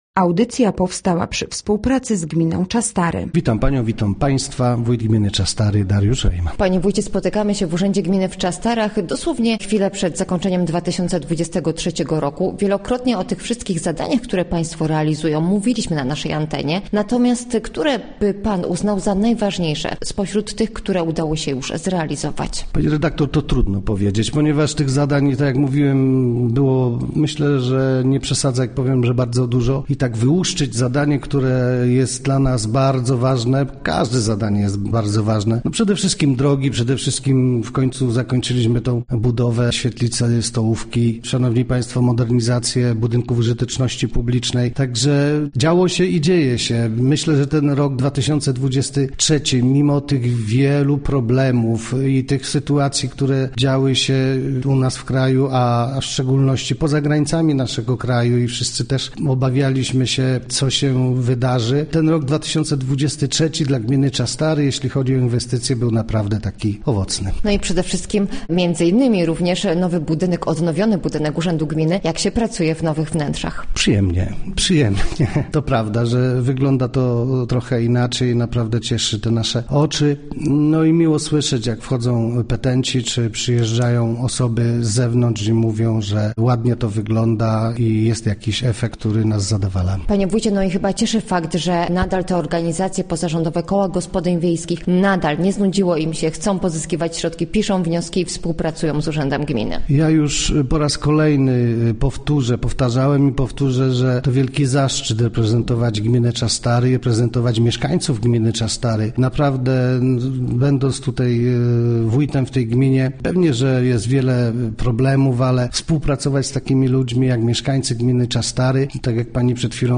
Gościem Radia ZW był Dariusz Rejman, wójt gminy Czastary